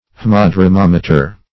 Meaning of haemadromometer. haemadromometer synonyms, pronunciation, spelling and more from Free Dictionary.
Haemadromometer \H[ae]m`a*dro*mom"e*ter\ (-dr[-o]*m[o^]m"[-e]*t[~e]r), n.